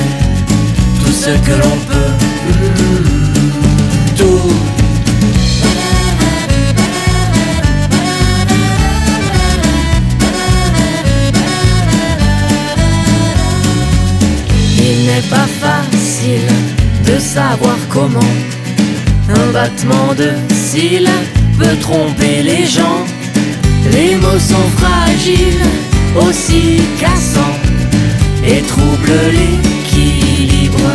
Le son est chaud, les deux voix s'accordent, se répondent.